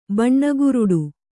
♪ baṇṇaguruḍu